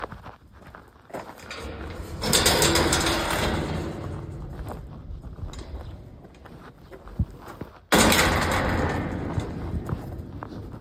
Bruit portail